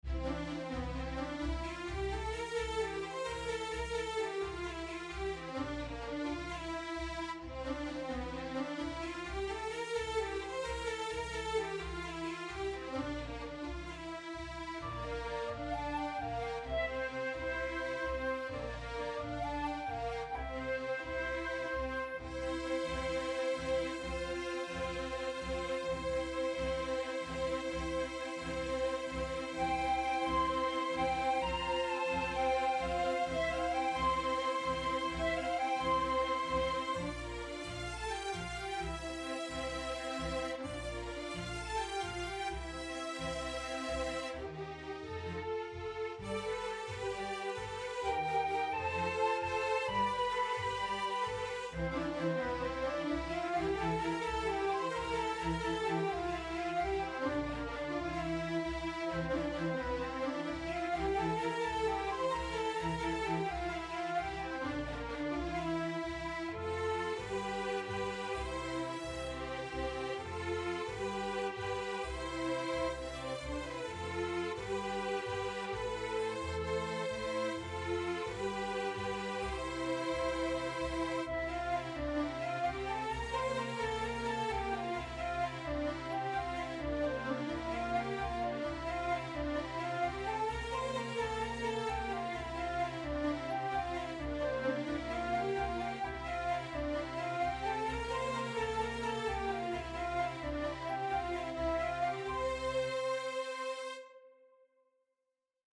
Underscore
Reduced arrangement